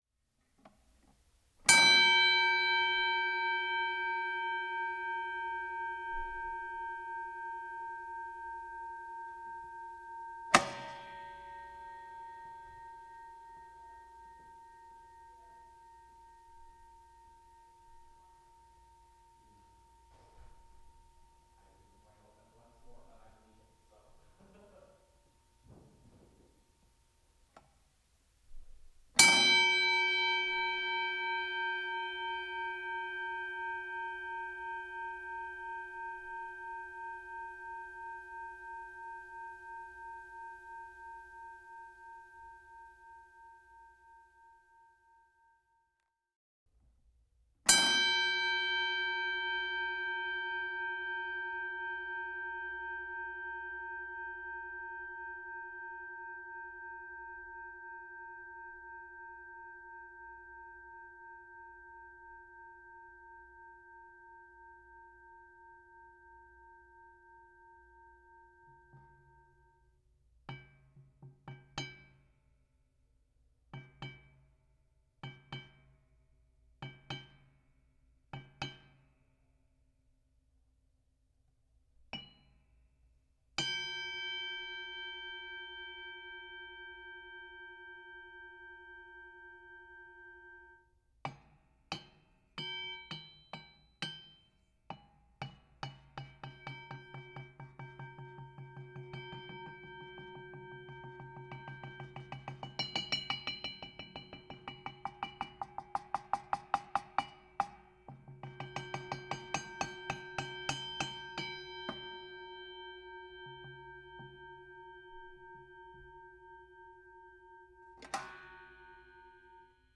180820 Boxing bell, various rings dings pulses close distant, Soulpepper TORONTO
bell boxing ding ring sound effect free sound royalty free Sound Effects